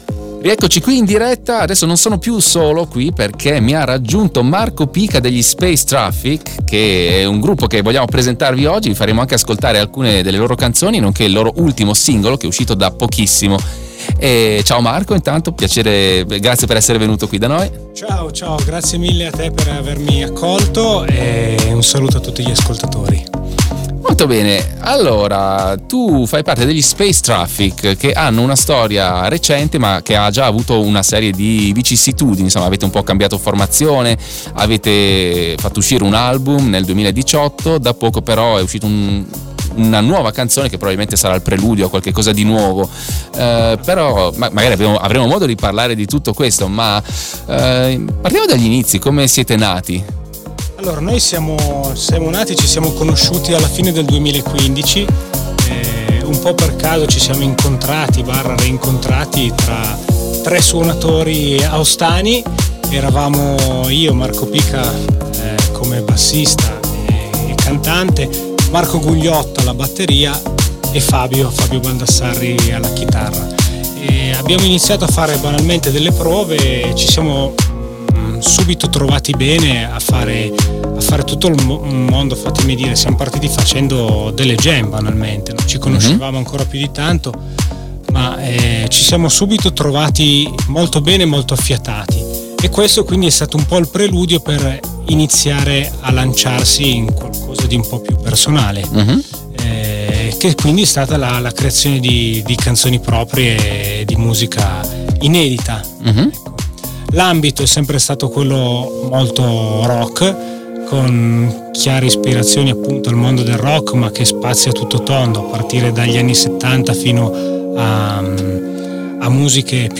Intervista diretta radio